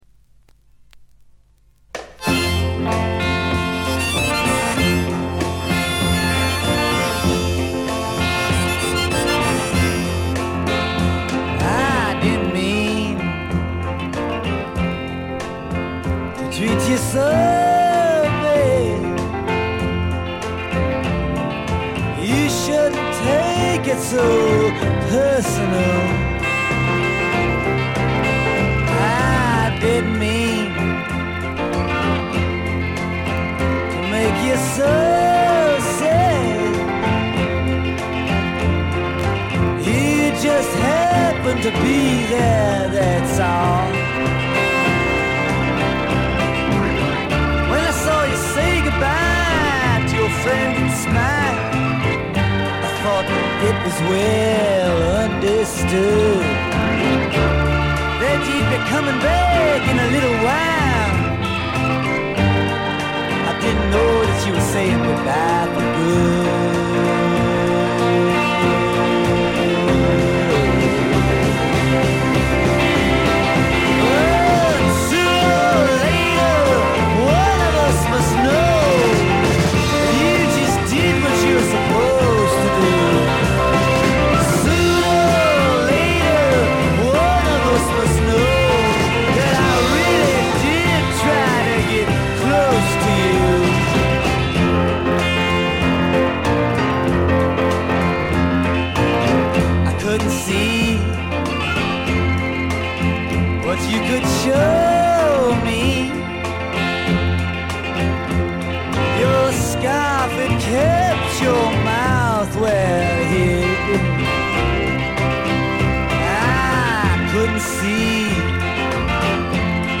モノラルの濃厚な味わいを堪能できます。
試聴曲は現品からの取り込み音源です。
vocals, guitar, harmonica, piano